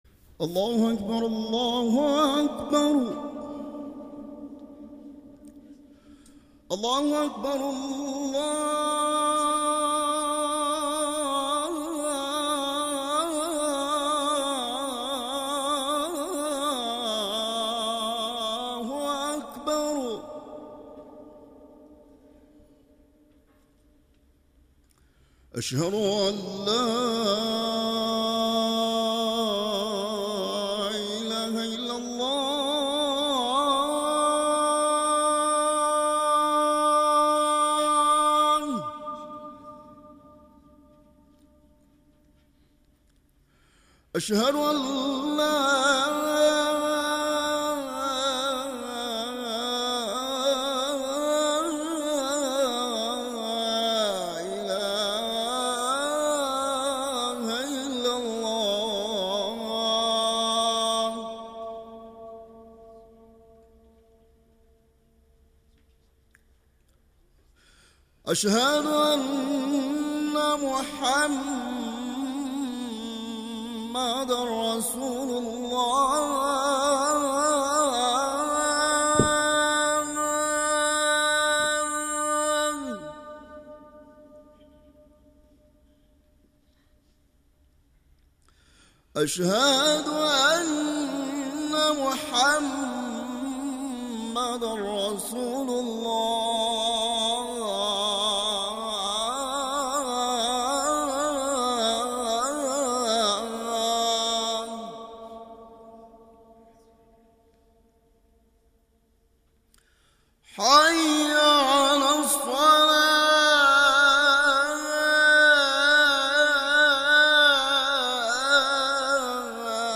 2014 Les prêches du Vendredi Télecharger le fichier MP3